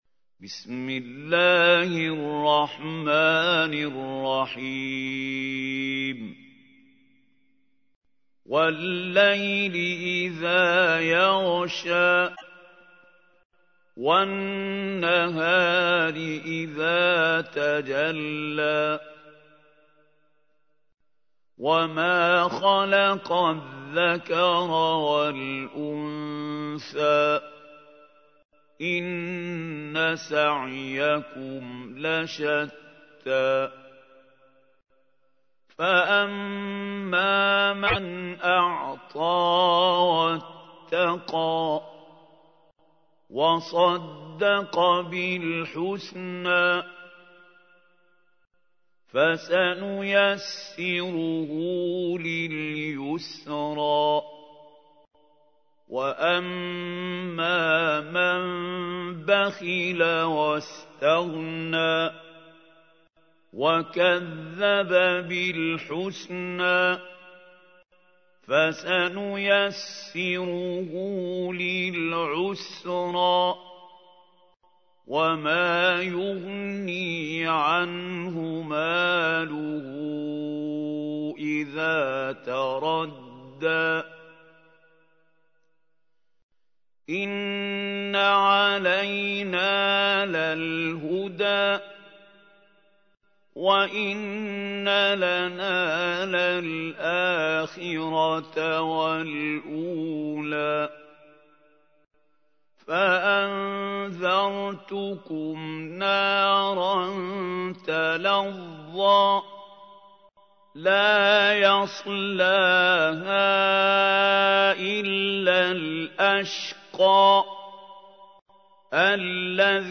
تحميل : 92. سورة الليل / القارئ محمود خليل الحصري / القرآن الكريم / موقع يا حسين